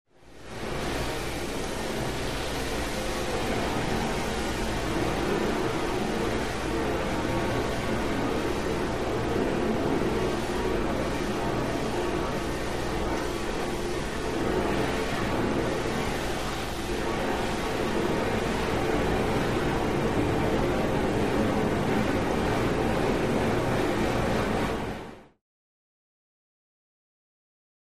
Hose, Spray
Spraying Metal Car Roof In A Large Garage